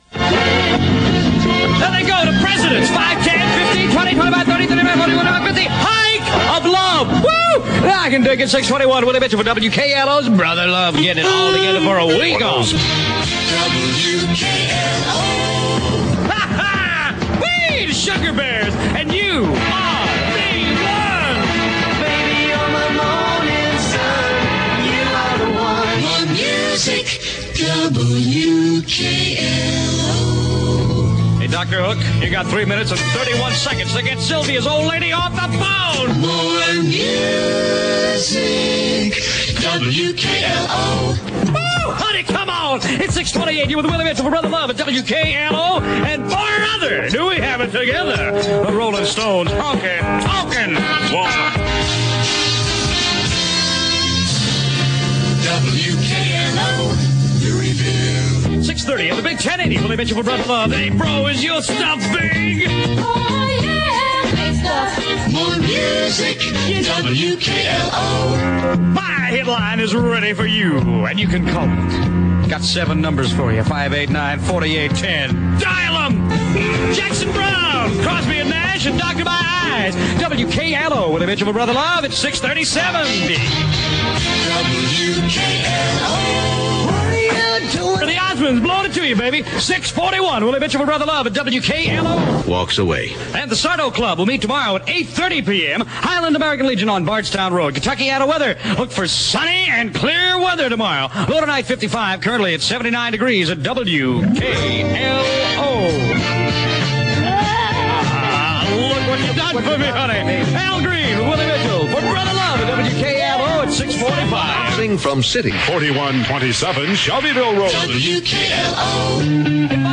on air at WKLO Louisville, KY